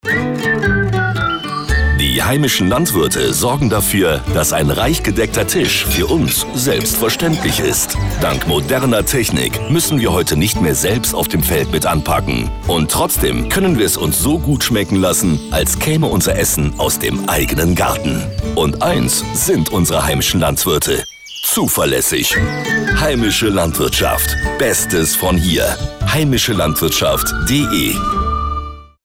Radiospots zum Herunterladen